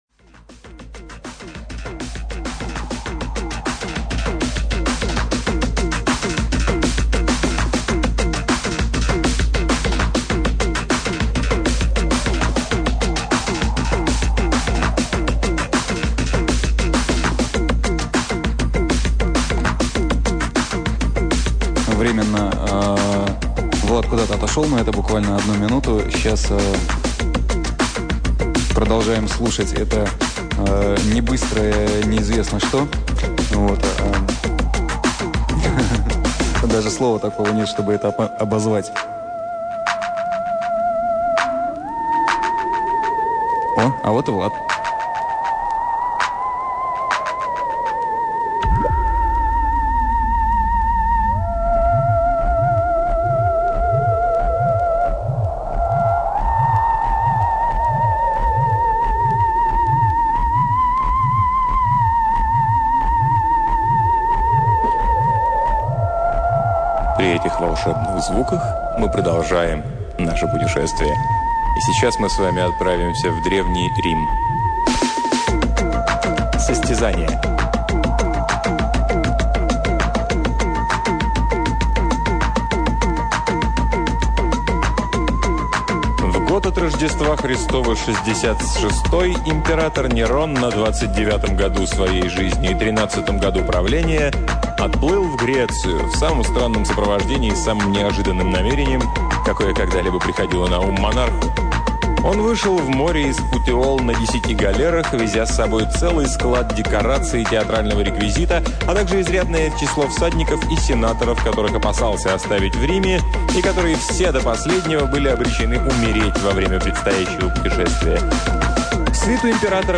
Аудиокнига Артур Конан Дойл — Состязание